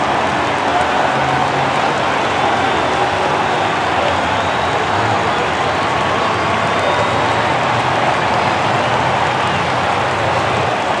ambience_crowd_nascar.wav